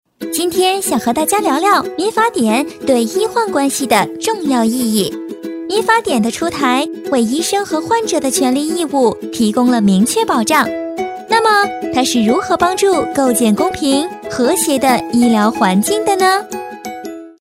女277-角色-女童
女277角色广告专题 v277
女277-角色-女童.mp3